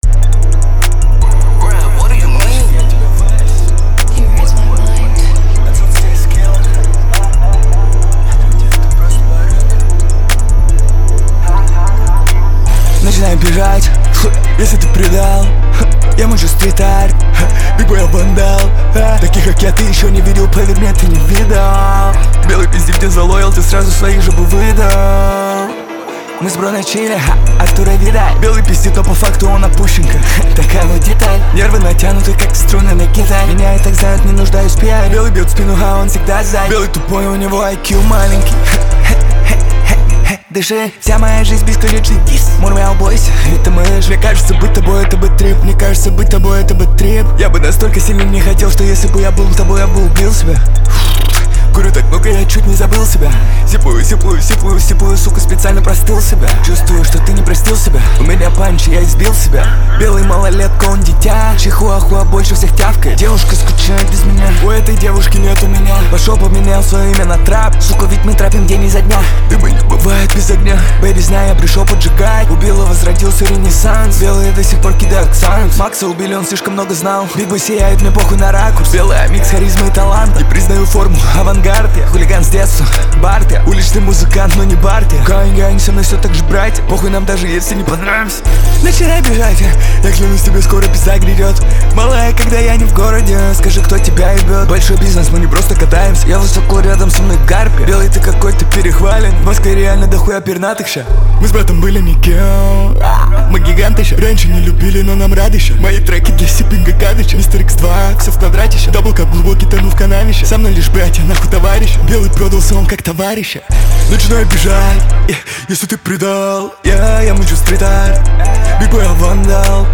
Жанр: Русские